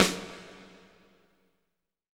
Index of /90_sSampleCDs/Roland L-CDX-01/SNR_Snares 4/SNR_Sn Modules 4
SNR POP S0OR.wav